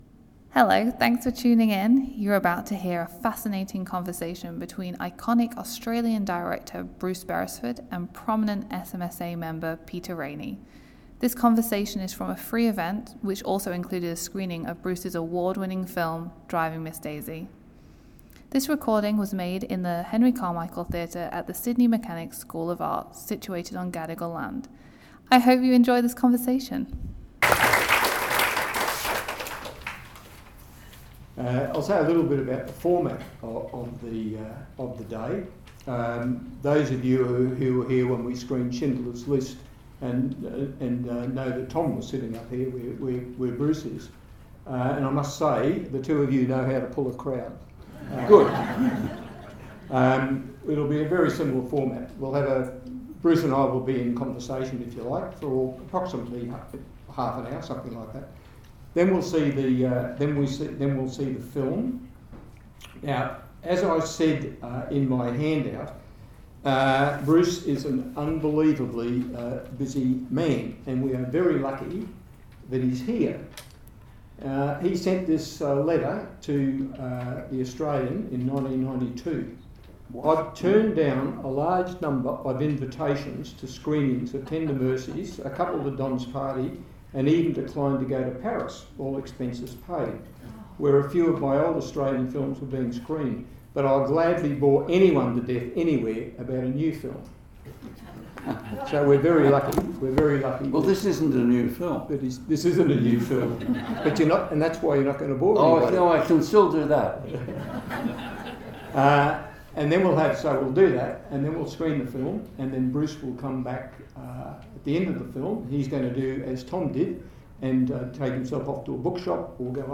This conversation was part of a free event hosted by the SMSA, including a screening of Bruce’s classic film Driving Miss Daisy.